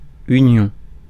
Ääntäminen
IPA: [y.njɔ̃]